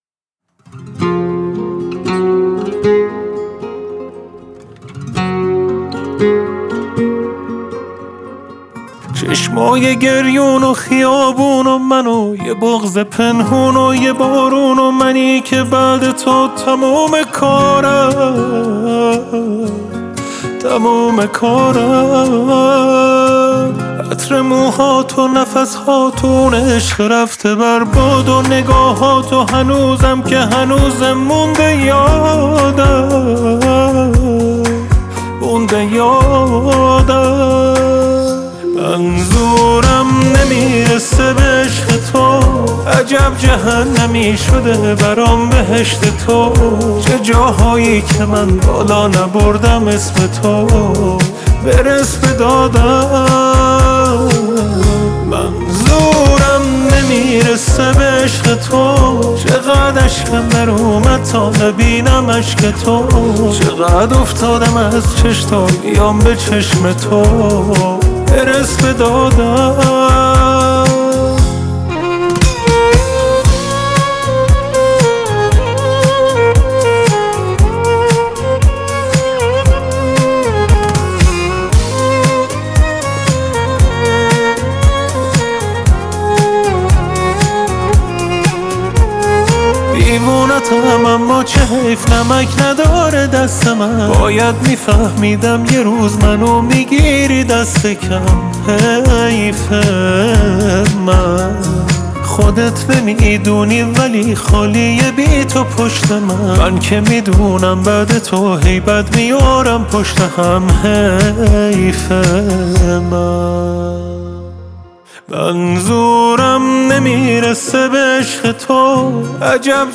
دانلود آهنگ غمگین